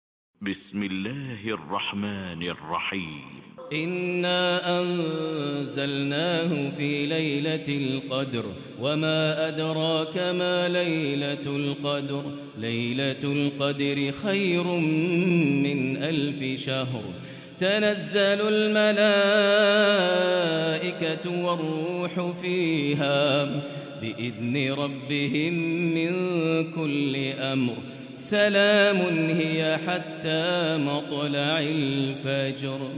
Tarawih prayer from the holy Mosque